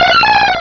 pokeemerald / sound / direct_sound_samples / cries / skiploom.aif